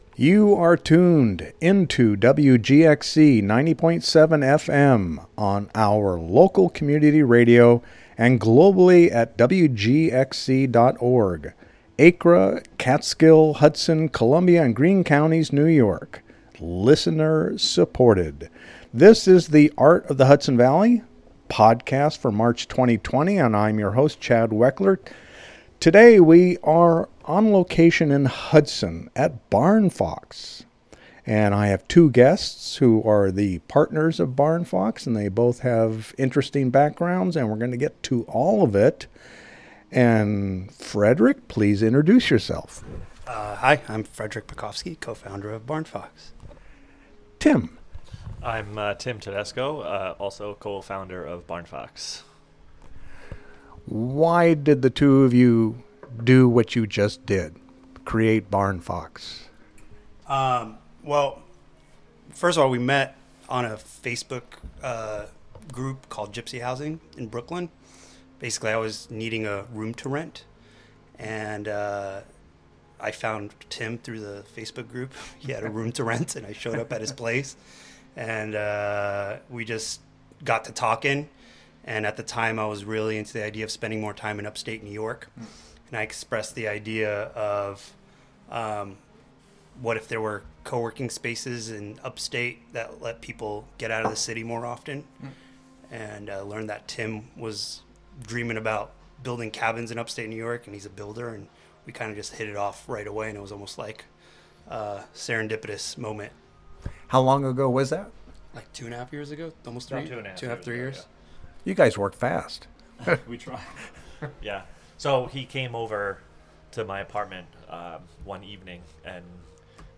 A conversation